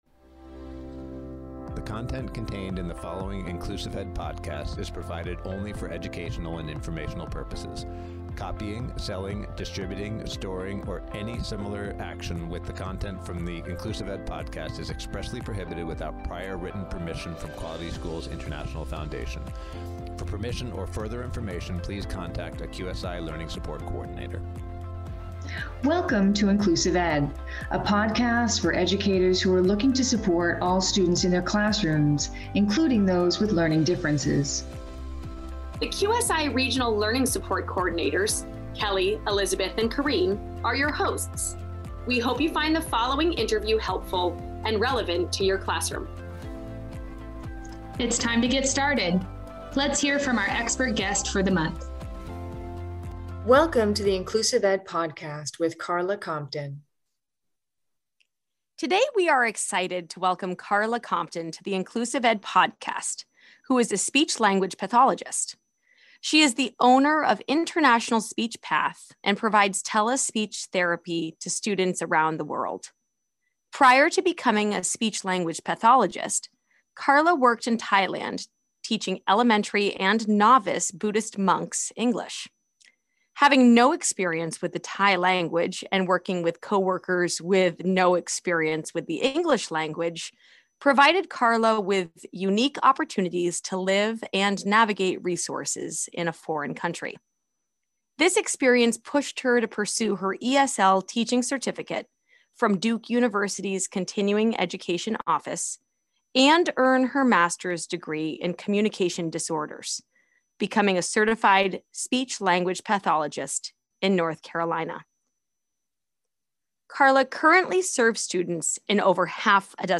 Check out my podcast interview!!!